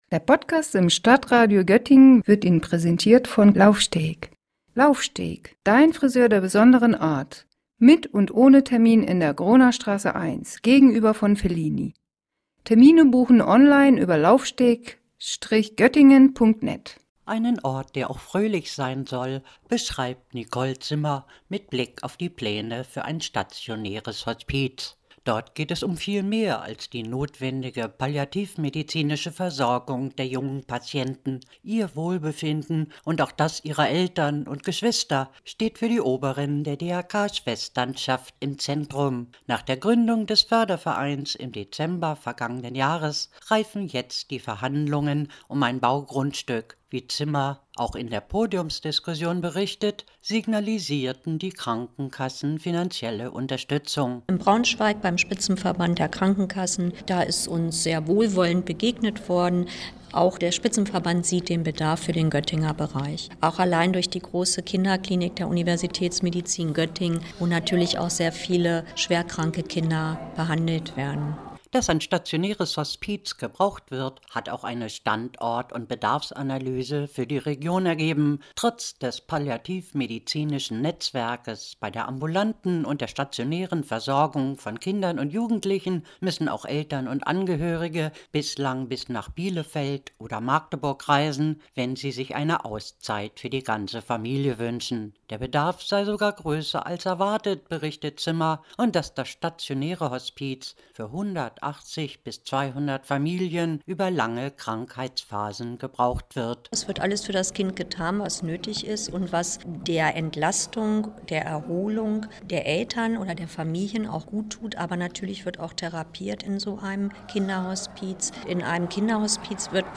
Bei einer Podiumsdiskussion im Freizeit Inn unter dem Motto „Göttingen braucht ein Hospiz für Kinder und Jugendliche“ informierte der Verein über seine aktuellen Pläne.